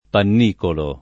pannicolo [ pann & kolo ]